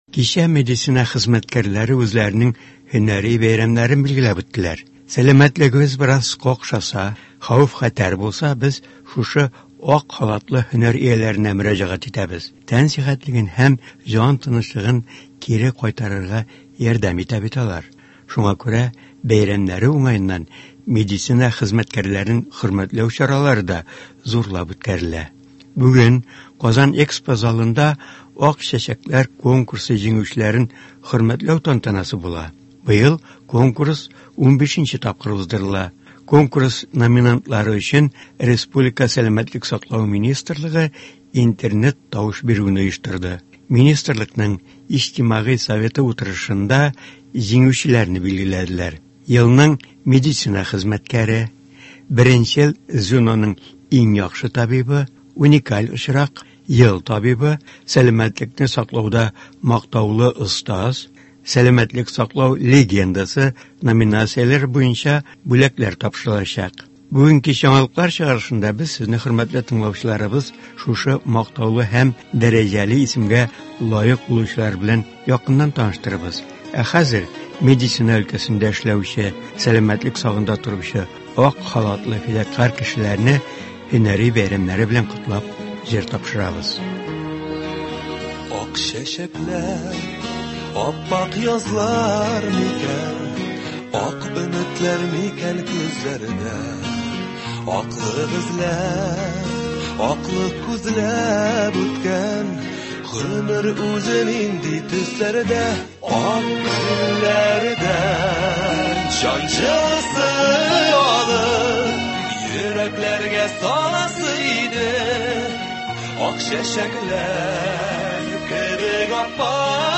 Туры эфир (18.06.23) | Вести Татарстан